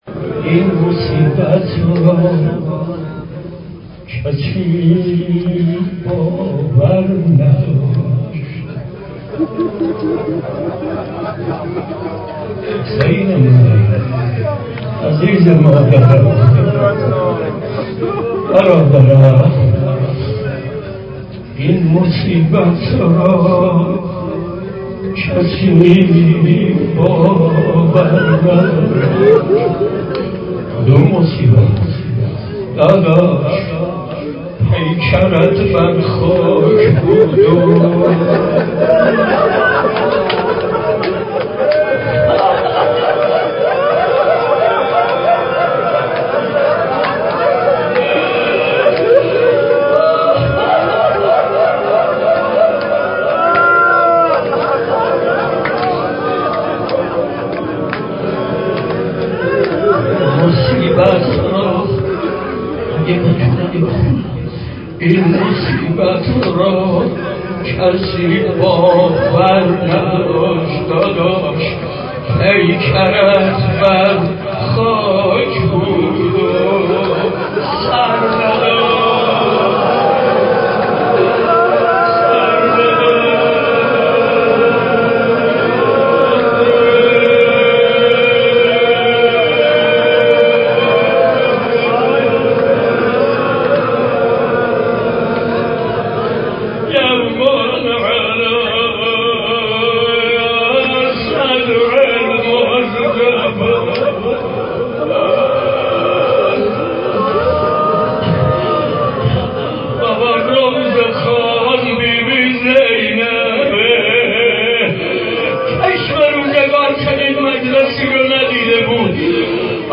نکته: به دلیل اینکه صوت مراسم به صورت محیطی ضبط شده، ممکن است از کیفیت خوبی برای شنیدن برخوردار نباشد.